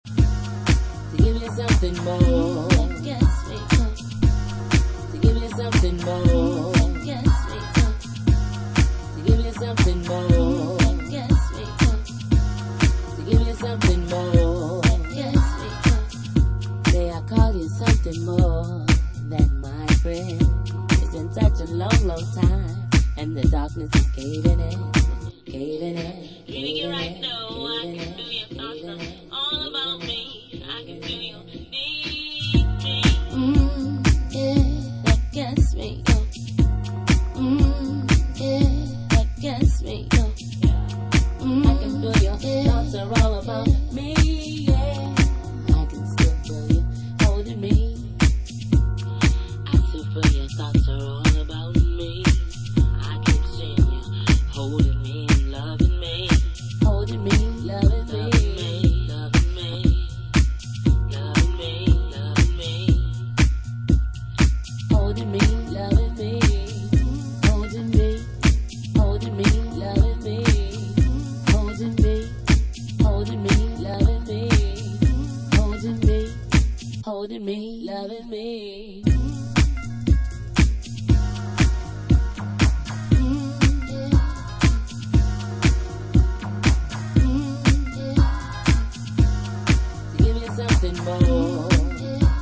vocalist
House